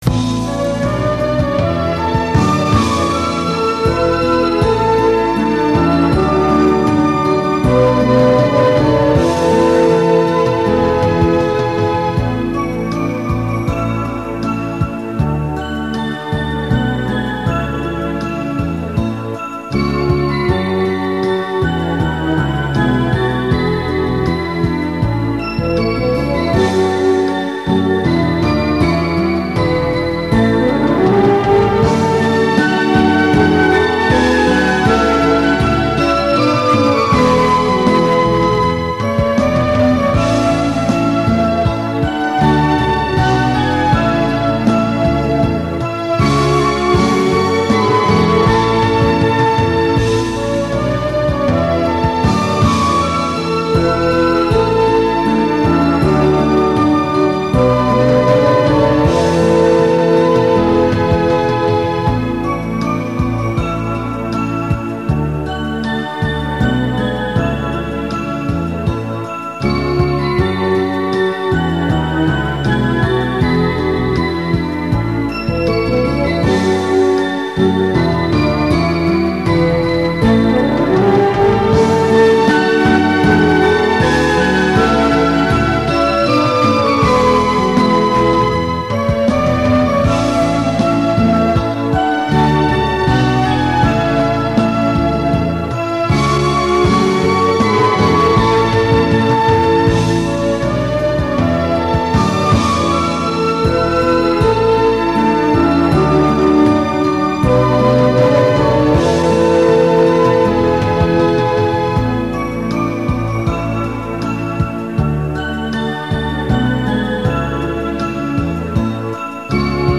市民歌メロディーのみ (音声ファイル: 2.5MB)